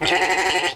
sheep2.wav